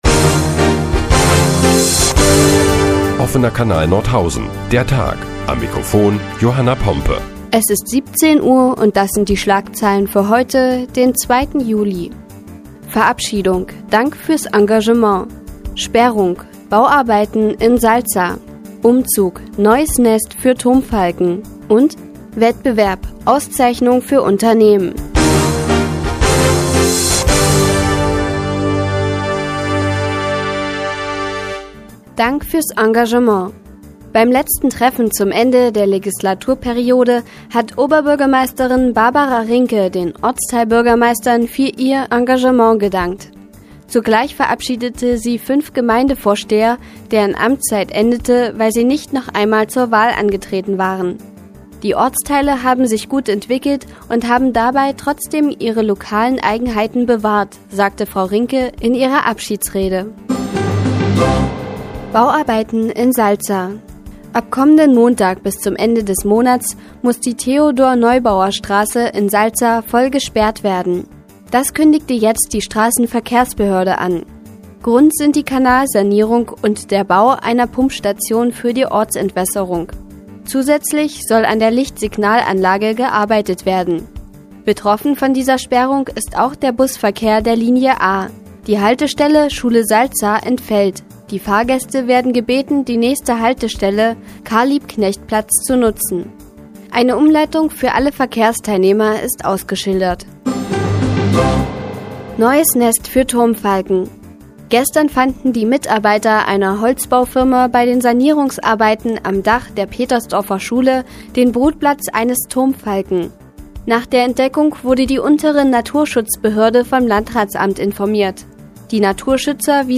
Die tägliche Nachrichtensendung des OKN ist nun auch in der nnz zu hören. Heute geht es unter anderem um Bauarbeiten in Salza und ein neues Nest für Turmfalken.